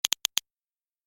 دانلود آهنگ کلیک 13 از افکت صوتی اشیاء
جلوه های صوتی
دانلود صدای کلیک 13 از ساعد نیوز با لینک مستقیم و کیفیت بالا